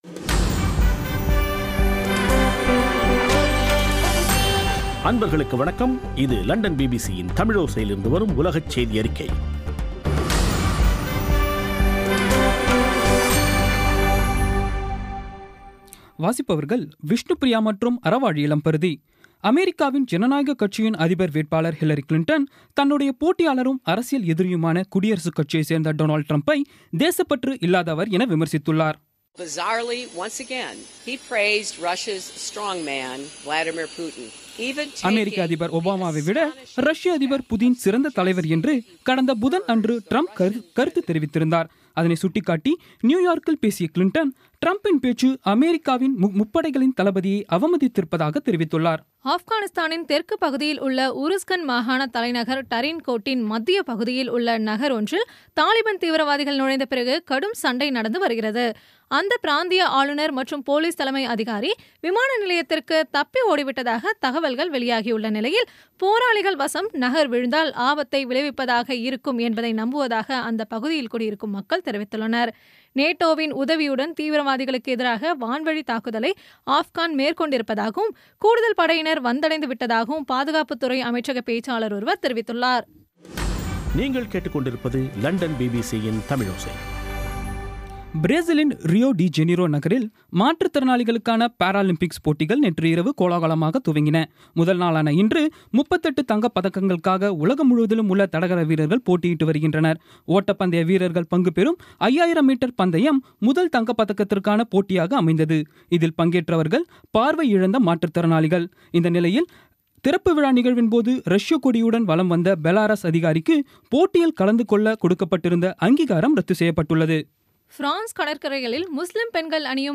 இன்றைய (ஆகஸ்ட் 8ம் தேதி ) பிபிசி தமிழோசை செய்தியறிக்கை